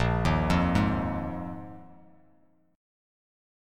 Bb7sus4 chord